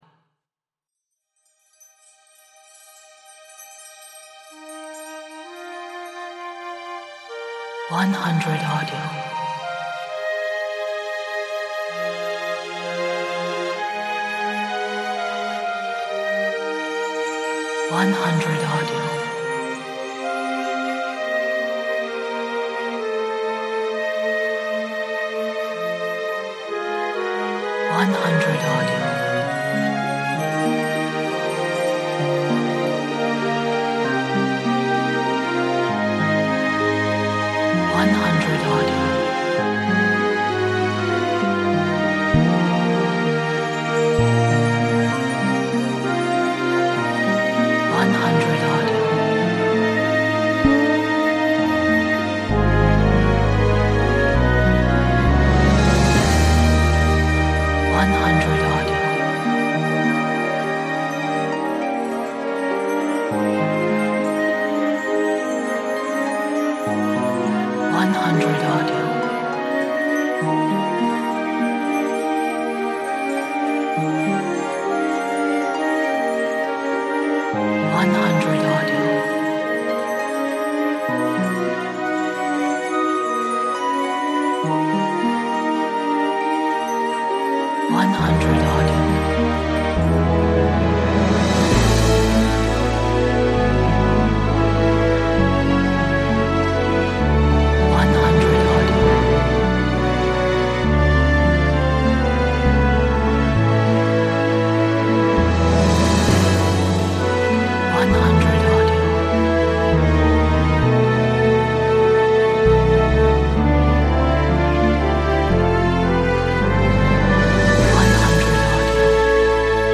Life-affirming Major Symphonic Theme best for Cinema
最适合电影的肯定生命的主要交响乐主题